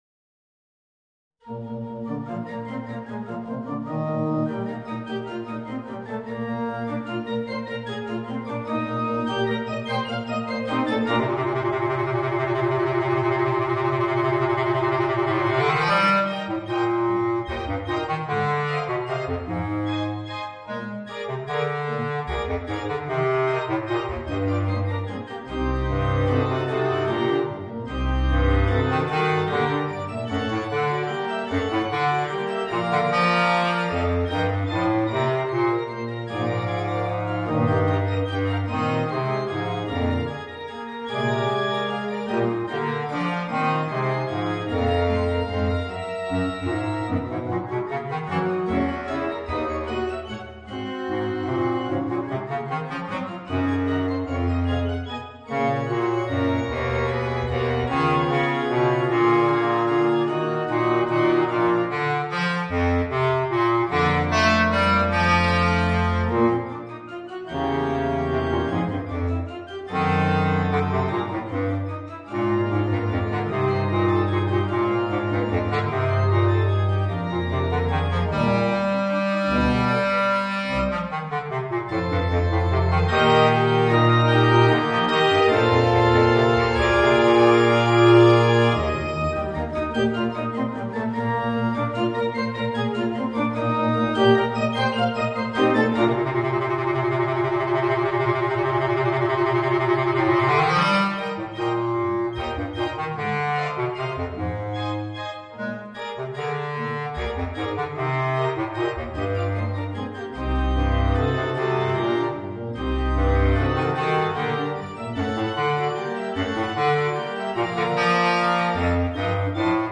Bassklarinette & Klavier